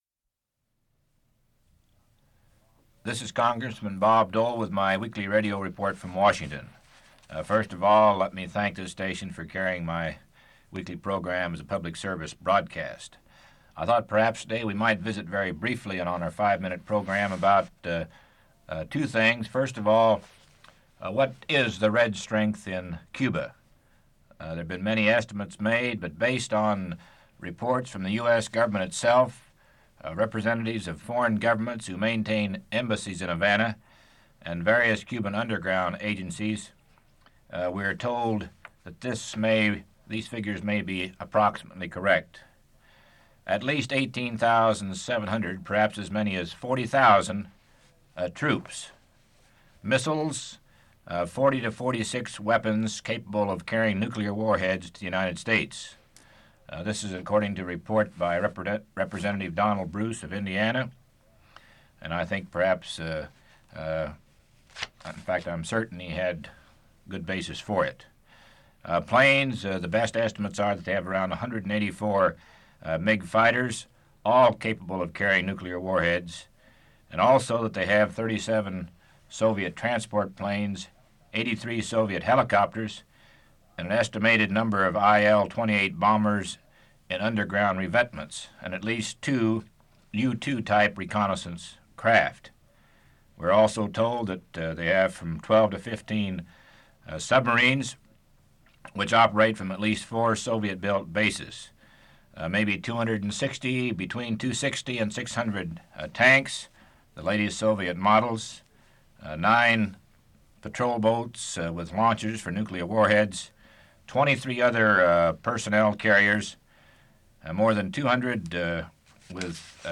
Part of Weekly Radio Report: Cuba & Wheat